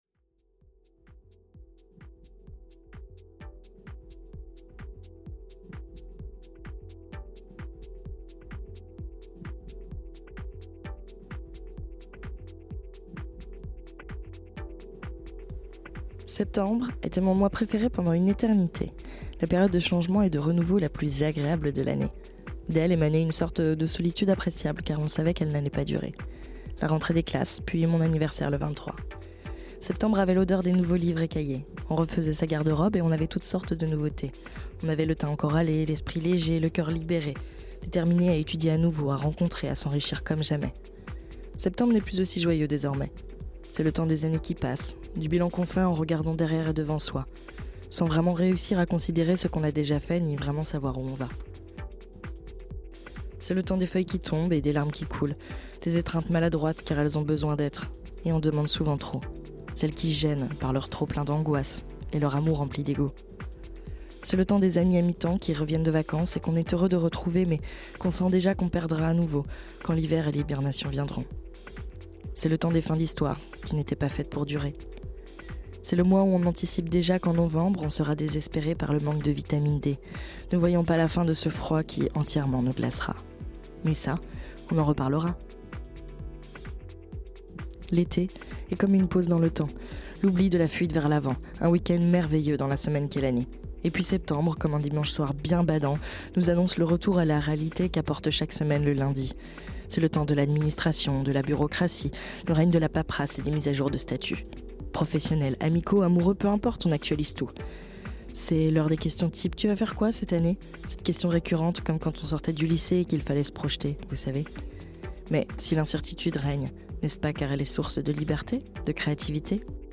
Soundtrack : Four Tet - SW9 9CL
Septembre, premier billet mélancolique d'une série longue comme un calendrier, dans laquelle musique et poésie pathétique souhaitent être mêlées.